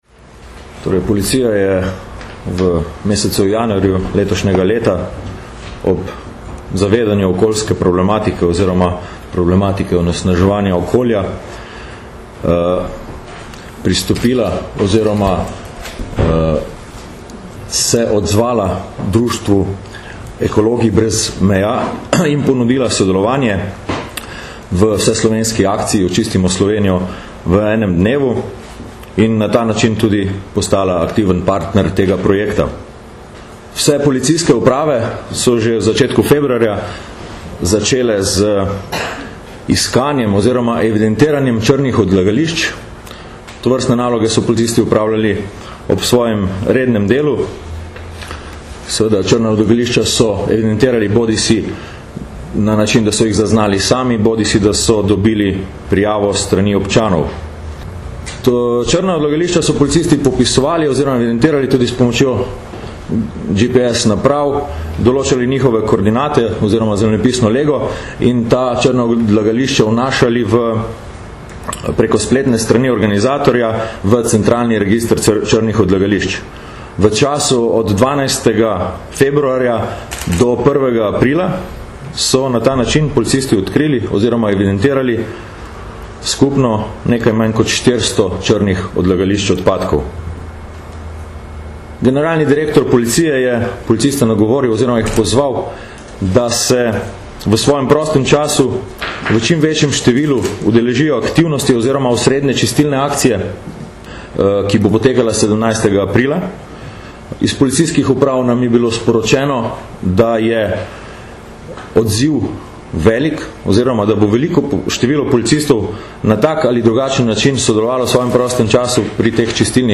Delo na področju ekološke kriminalitete med prednostnimi nalogami policije - informacija z novinarske konference
Kot partnerji v projektu Očistimo Slovenijo v enem dnevu smo policisti od začetka februarja do aprila na območju celotne države evidentirali skoraj 400 črnih odlagališč odpadkov. Na današnji novinarski konferenci smo podrobneje predstavili prizadevanja policije za čistejše okolje oz. naše delo na področju ekološke kriminalitete.